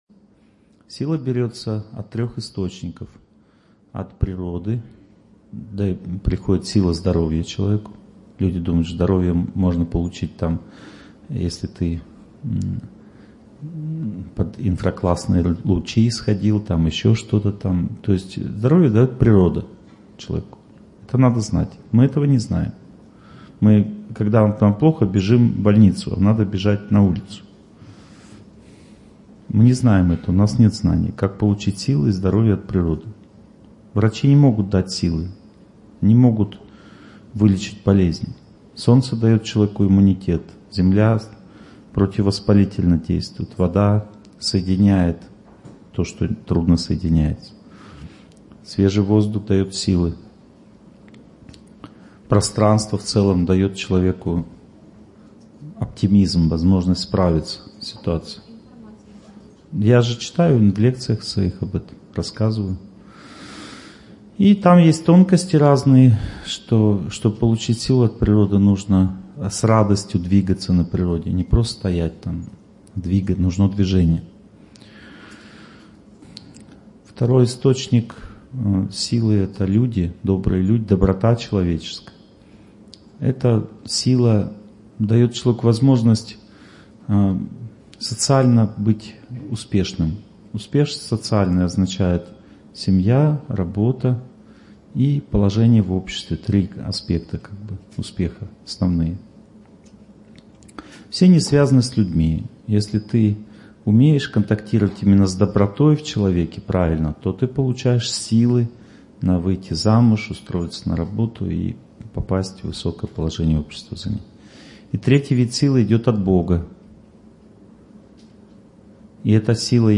Жизнь в любви. Лекция 2